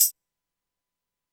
Percs
Havoc Tam 2.wav